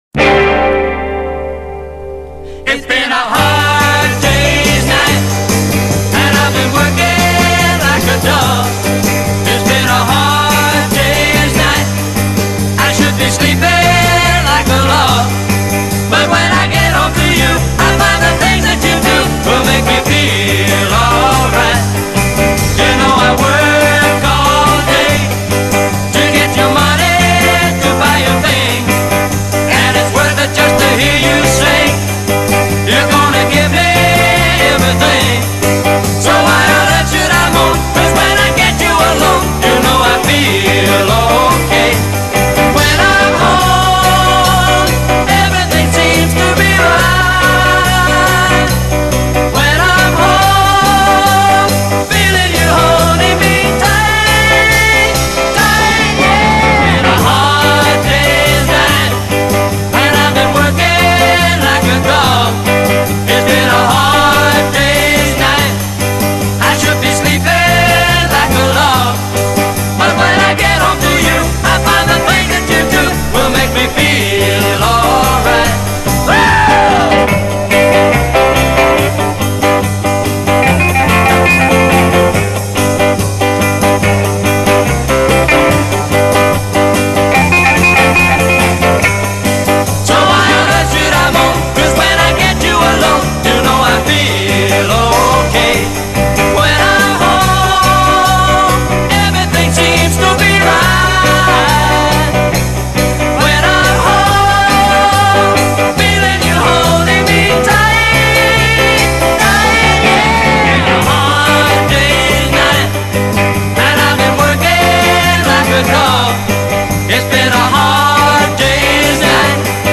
Очень близко к оригиналу.